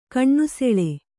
♪ kaṇṇu seḷe